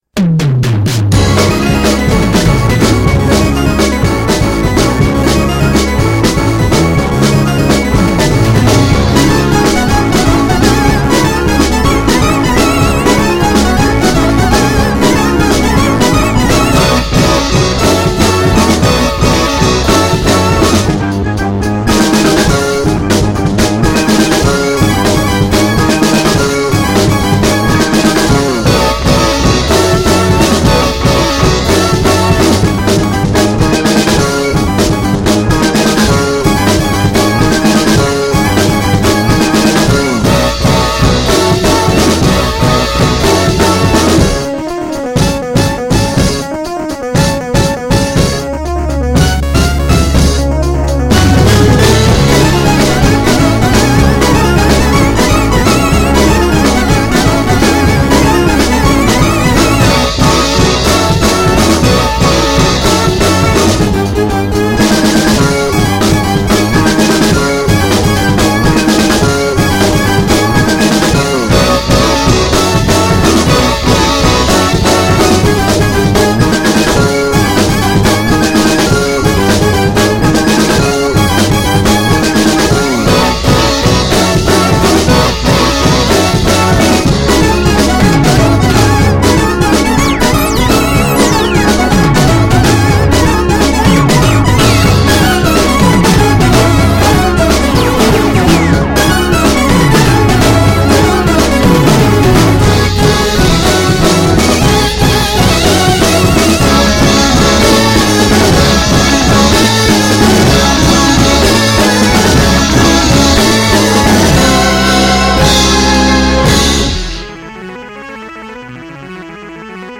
un rock enérgique à base de synthés copieux.
Petits frères d’Aavikko option jeux vidéos.